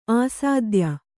♪ āsādya